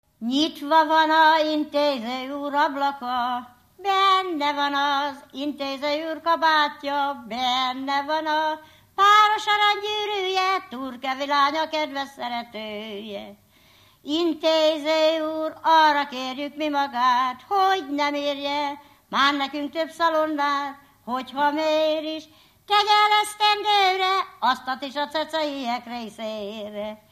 Alföld - Jász-Nagykun-Szolnok vm. - Túrkeve
ének
Stílus: 3. Pszalmodizáló stílusú dallamok